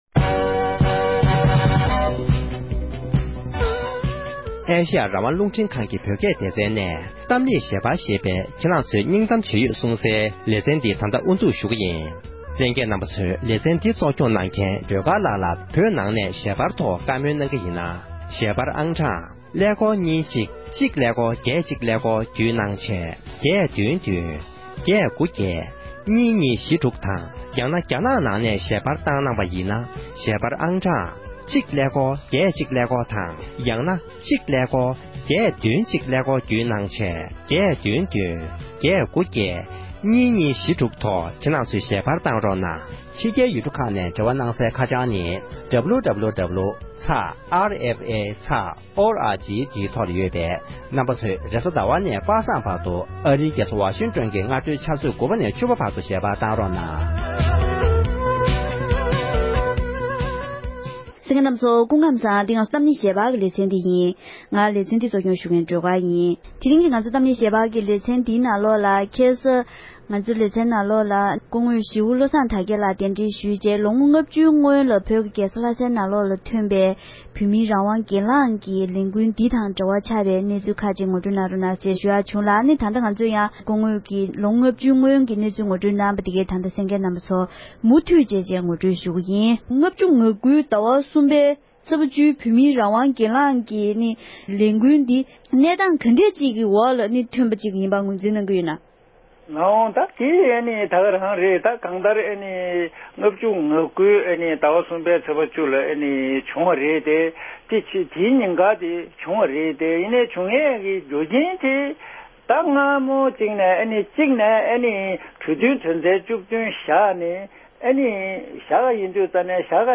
བོད་ནང་རང་དབང་སྒེར་ལངས་ཀྱི་ལས་འགུལ་ནང་དངོས་སུ་ཞུགས་མྱོང་མཁན་བཀའ་ཟུར་བློ་བཟང་དར་རྒྱས་ལགས་ཀྱི་ལྷན་གླེང་བ།